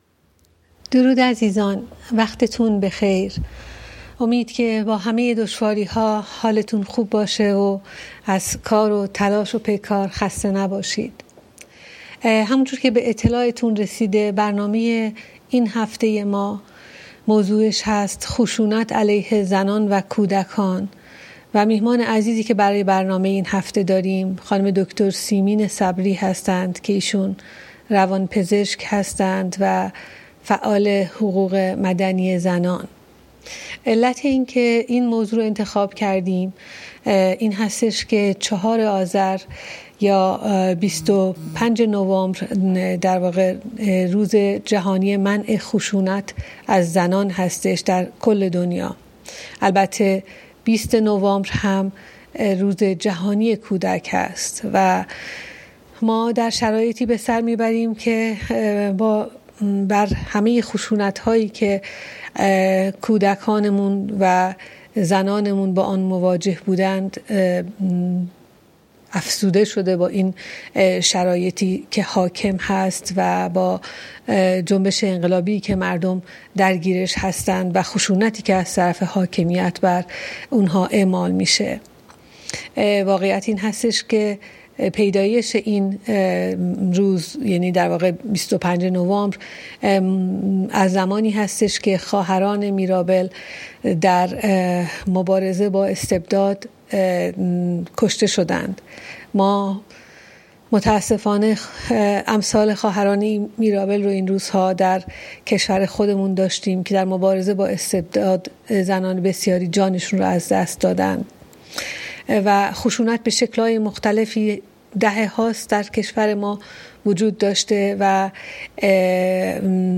جلسه پرسش و پاسخ یکشنبه ۶ آذر ۱۴۰۱ ساعت ۹ شب ایران